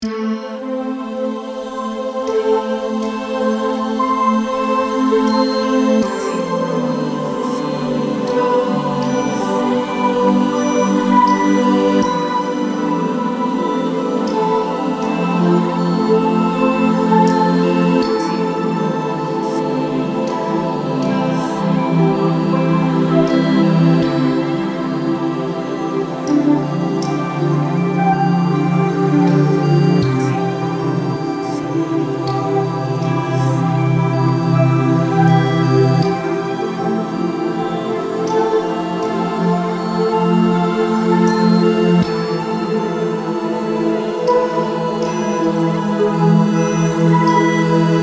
Dengan flow ritmis yang halus